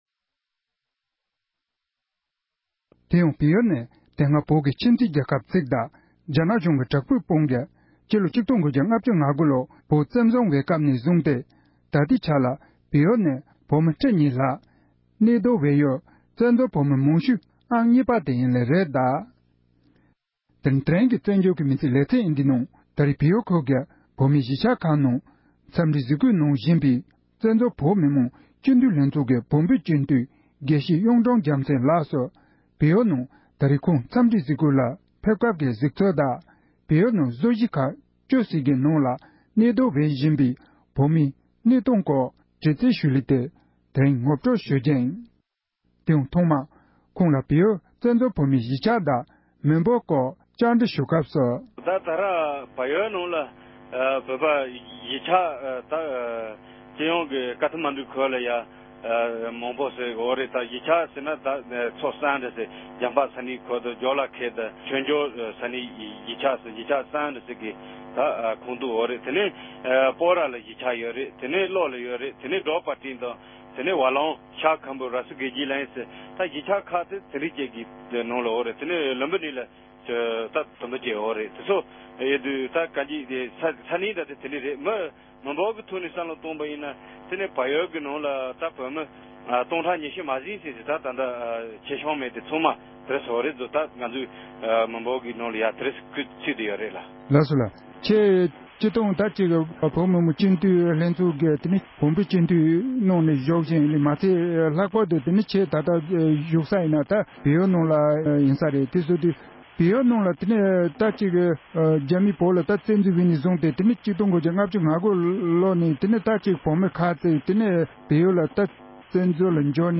བོད་མི་མང་སྤྱི་འཐུས་དགེ་བཤེས་གཡུང་དྲུང་རྒྱལ་མཚན་ལཌ་ཀྱིས་བལ་ཡུལ་དུ་ཡོད་པའི་བོད་མིའི་གནས་སྟངས་སྐོར་འགྲེལ་བརྗོད་གནང་བ།
སྒྲ་ལྡན་གསར་འགྱུར།